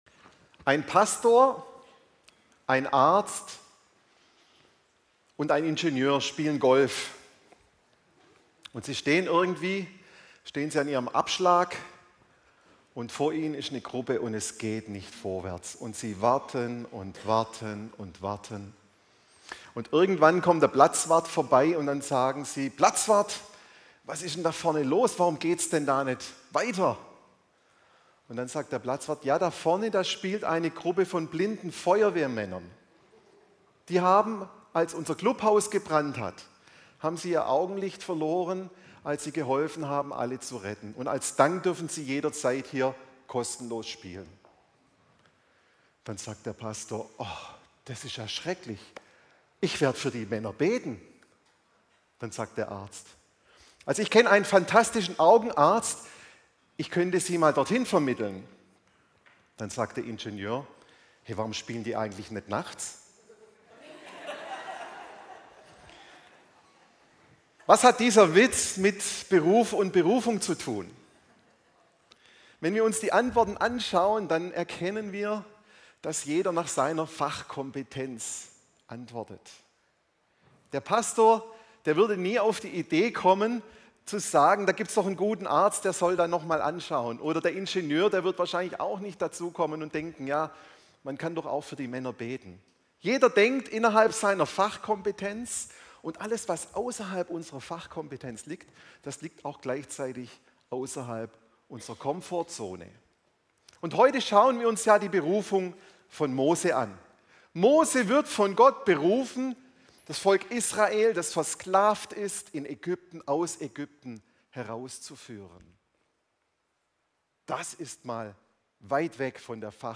Die neueste Predigt